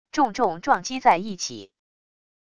重重撞击在一起wav音频